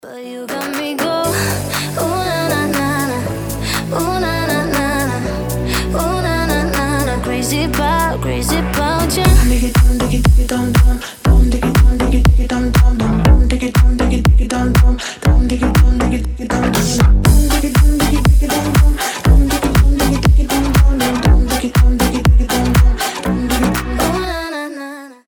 • Качество: 320, Stereo
заводные
женский голос
Club House
Dance Pop
Заводная танцевальная музыка с веселым проигрышем